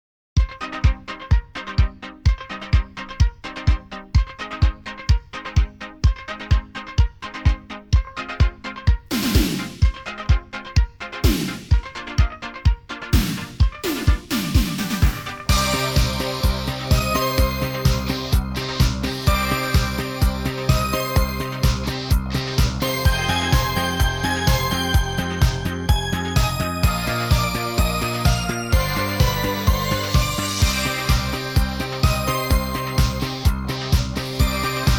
Жанр: Поп музыка / Русский поп / Русские